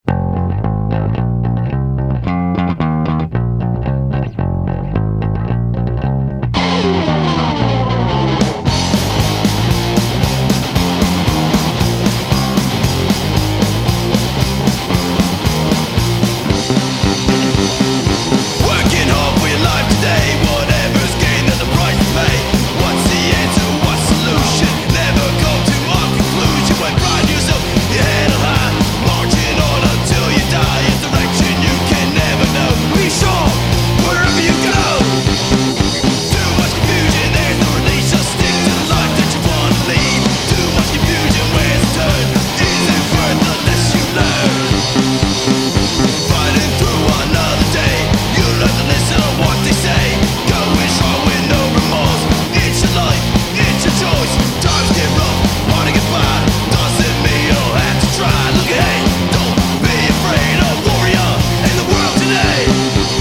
South Jersey punk trio